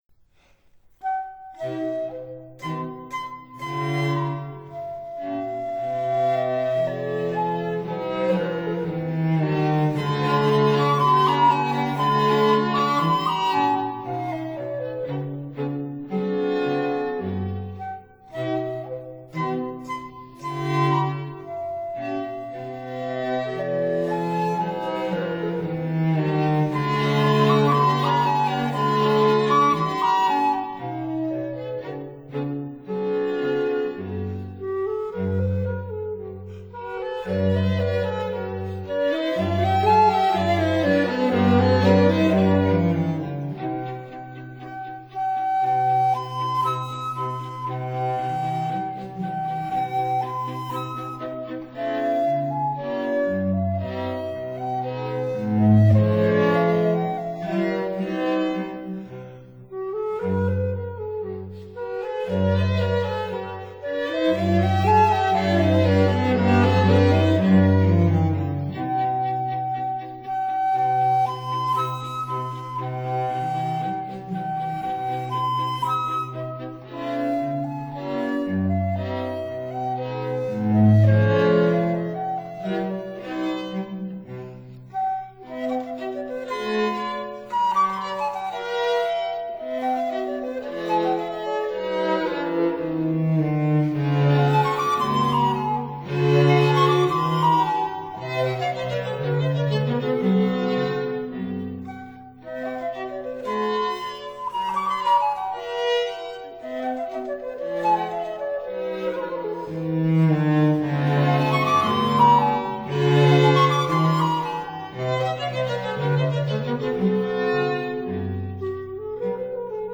transverse flute
violin
viola
cello